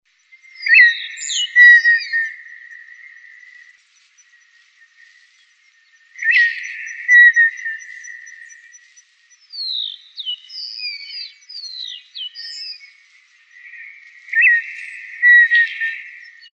Colorada (Rhynchotus rufescens)
Nombre en inglés: Red-winged Tinamou
Fase de la vida: Adulto
Localidad o área protegida: Tandil
Condición: Silvestre
Certeza: Vocalización Grabada
Untitled.mp3--Tandil--COLORADA.mp3